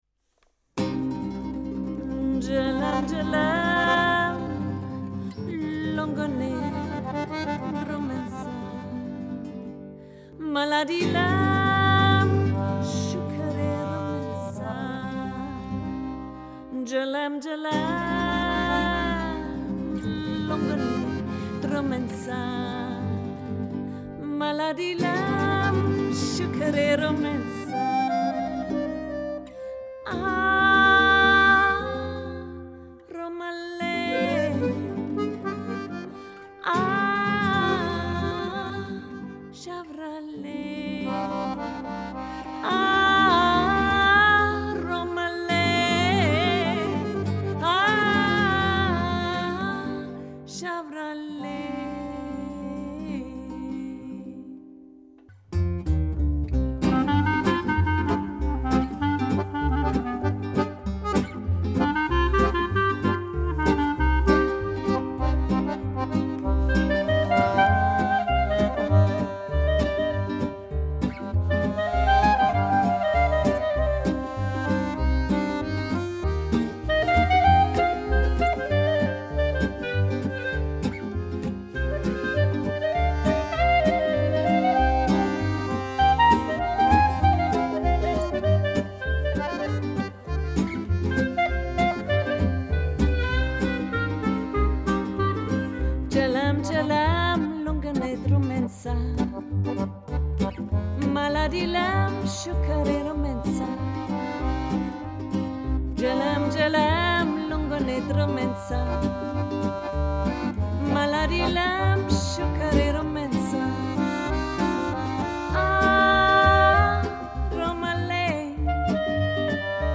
von tango, klezmer, gipsy über französische, spanische und südamerikanische musik bis hin zu jazzstandards und oldies –
gitarre, gesang
akkordeon, klavier, kontrabass, gesang
klarinette, flöte, saxophon, gesang
“Djelem Djelem” – Roma Song